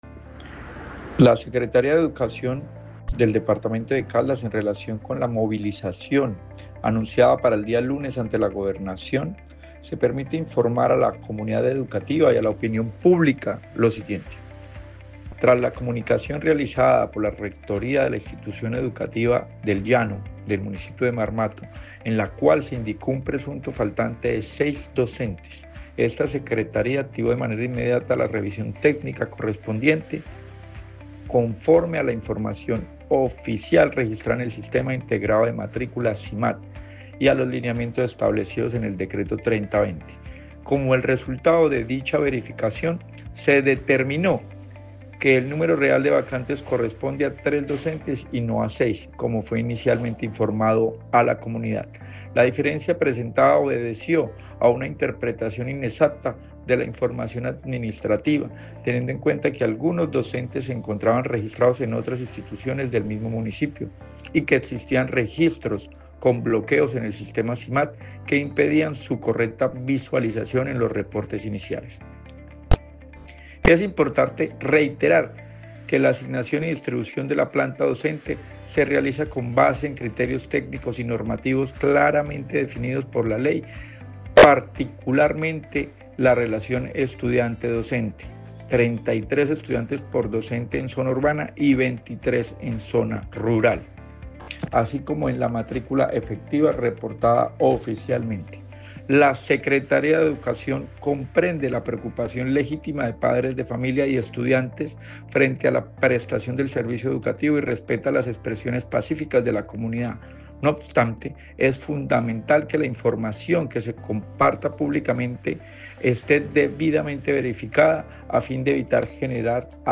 Luis Herney Vargas Barrera, secretario de Educación de Caldas